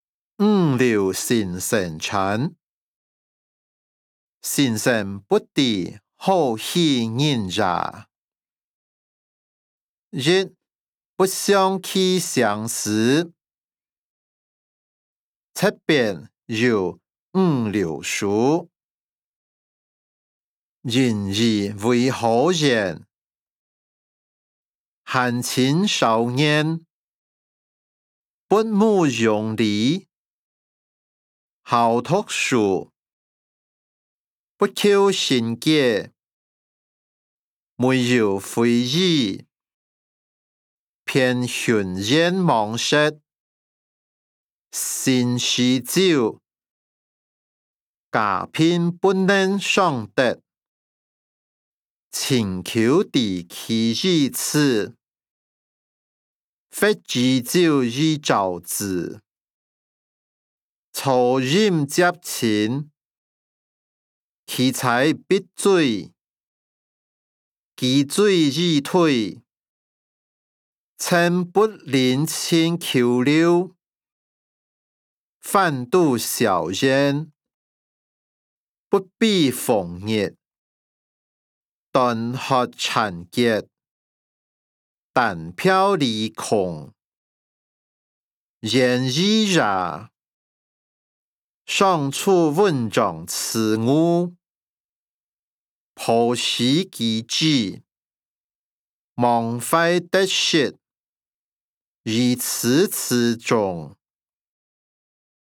歷代散文-五柳先生傳音檔(饒平腔)